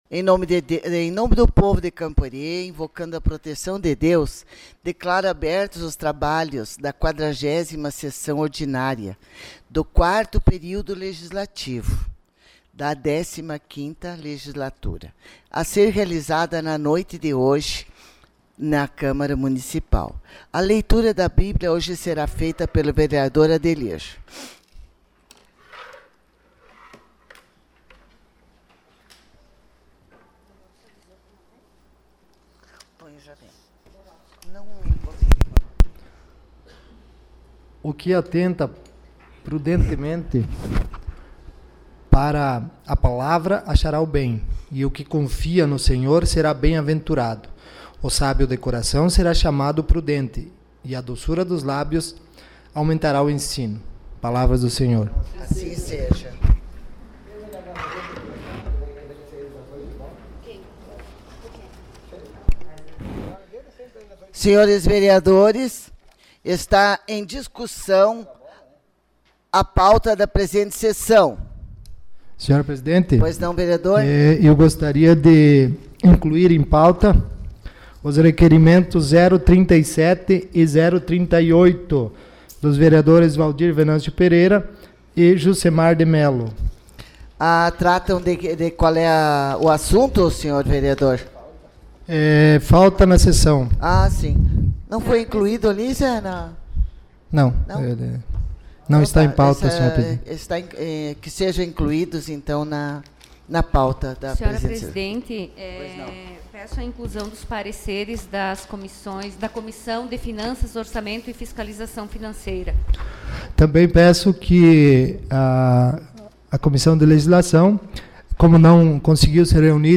Sessão Ordinária dia 10 de dezembro de 2020